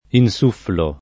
insufflo [ in S2 fflo ]